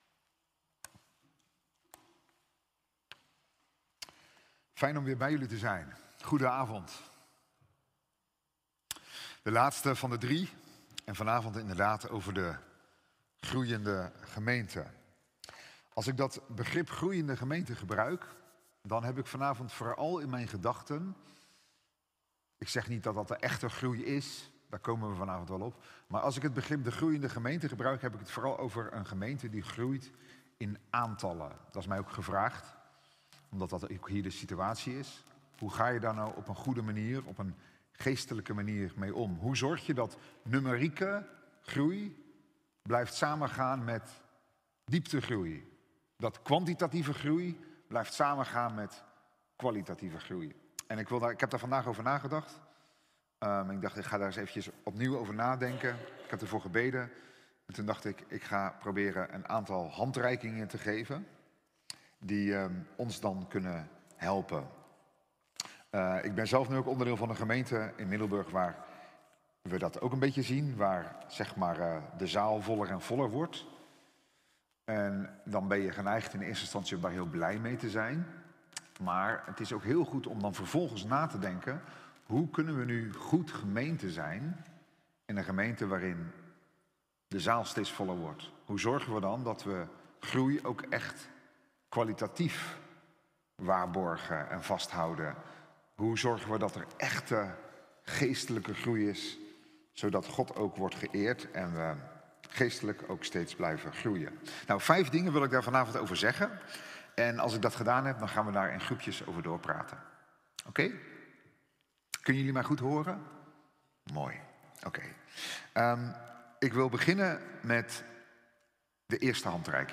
Themastudie